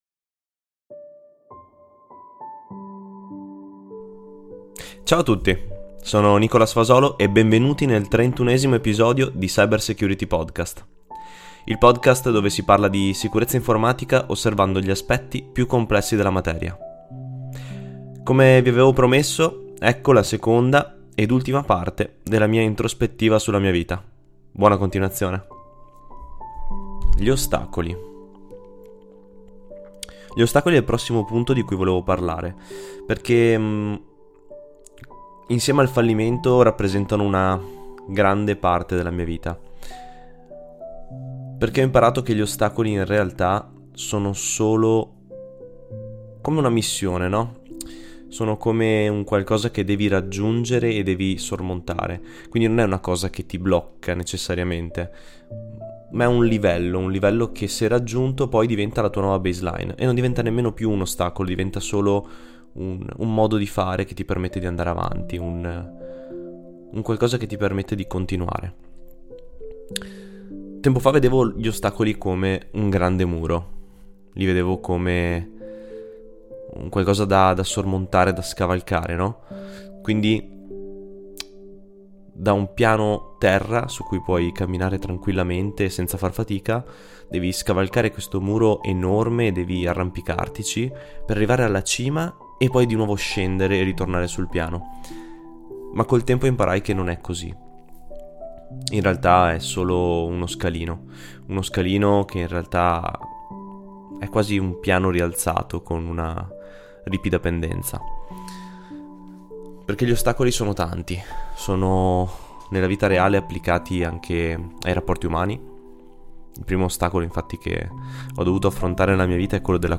In questo episodio tutto registrato in "one take" condividerò con voi alcune memorie della mia vita, raccontandone un pò la storia. L'obbiettivo di questo episodio è quello di trasmettere a chiunque ascolti alcuni dei concetti che ho imparato attraverso le mie esperienze ed ovviamente far conoscere chi c'è dietro la voce che sentite da diversi episodi in questo podcast.